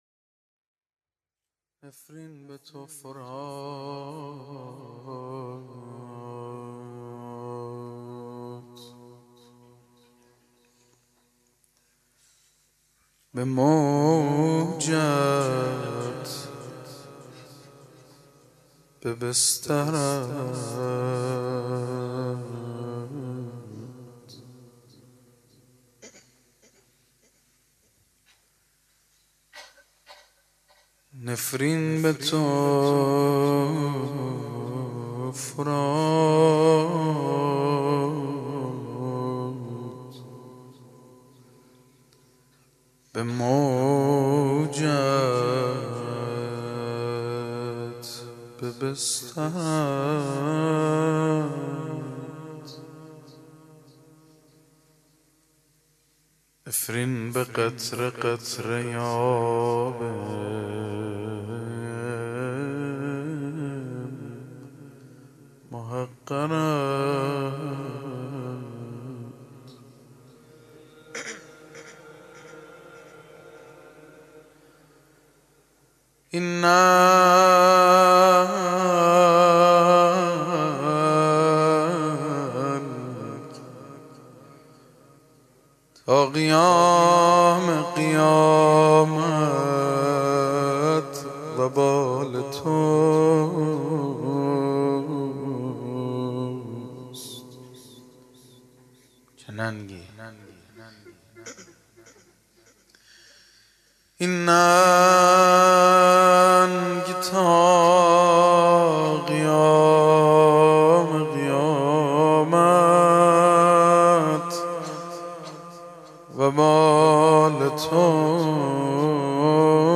01-Rozeh.mp3